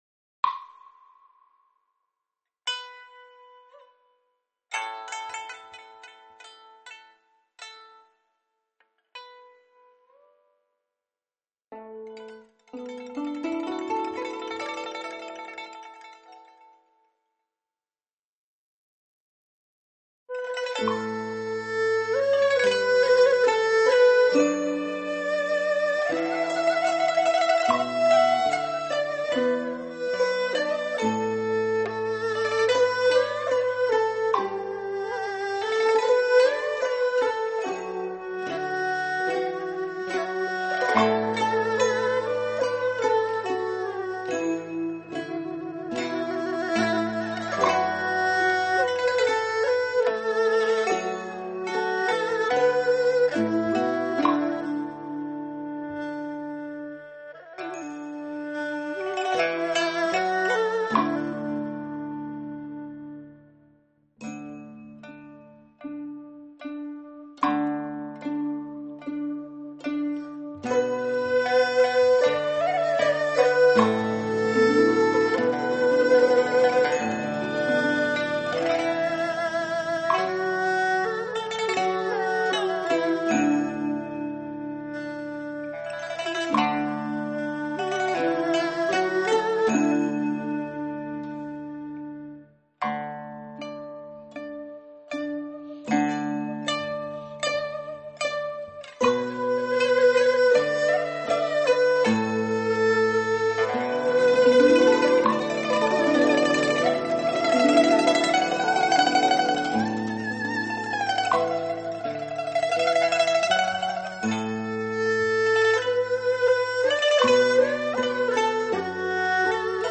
二胡、笙、古筝、琵琶、笛子、扬琴……这些传统的中国乐器像有生命一样发出了远古之音；